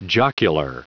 Prononciation du mot jocular en anglais (fichier audio)
Prononciation du mot : jocular